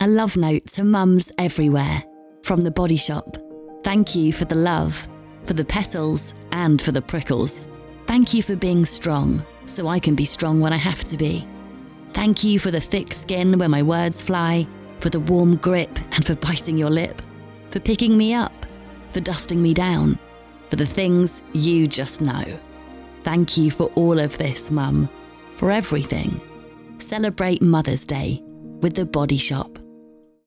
This charming spoken word ad, Petals and Prickles from The Body Shop, acknowledges the tough parts of the job in a way that makes you smile.
The-Body-Shop-Mothers-Day-Radio-Ad-2025.mp3